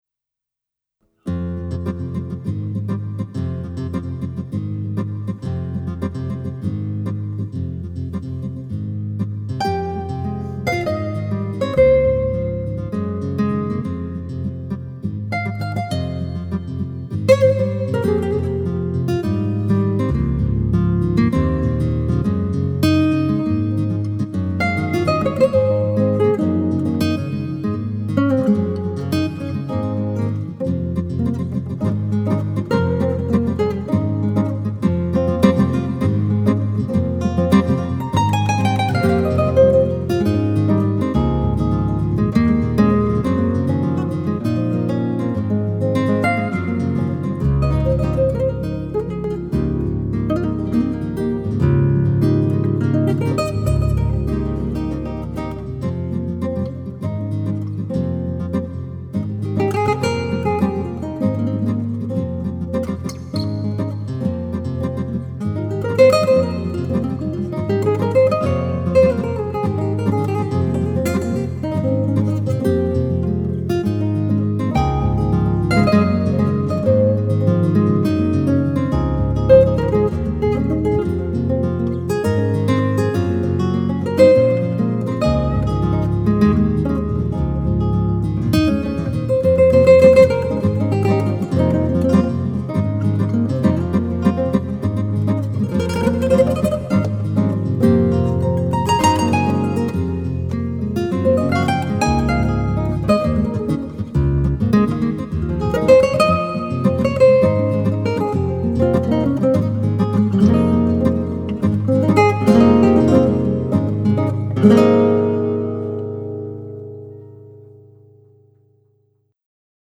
guitar
keyboard/composer.
It’s an odd little vamp in 7/4 that I still enjoy hearing